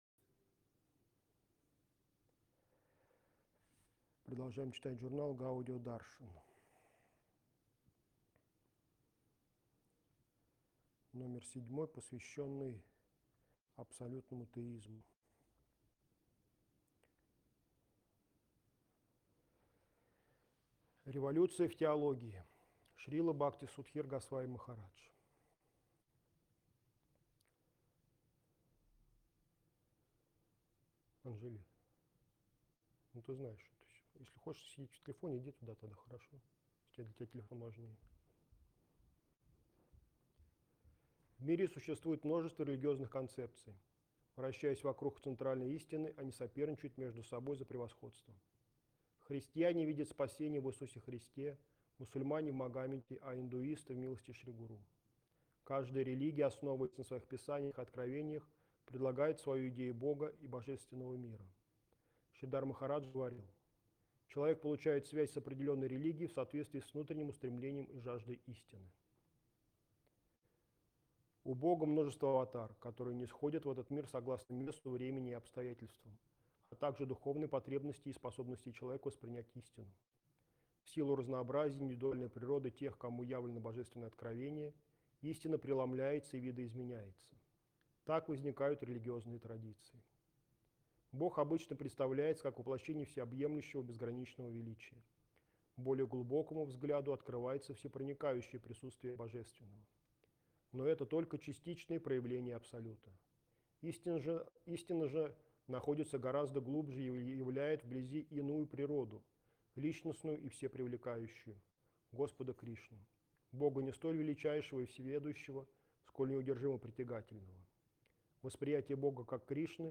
Москва, Кисельный